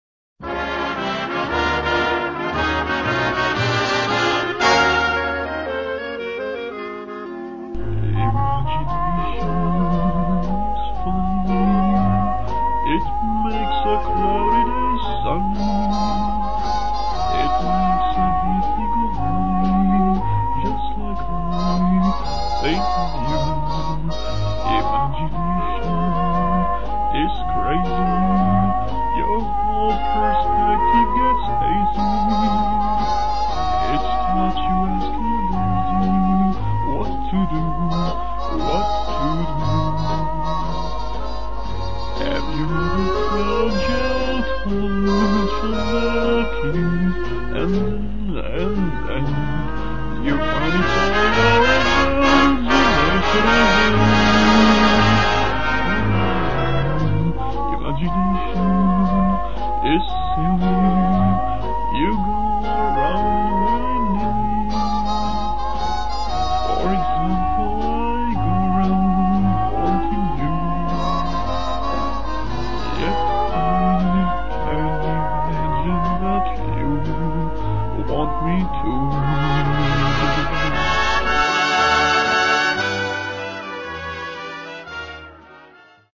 I love singing even though I stink at it.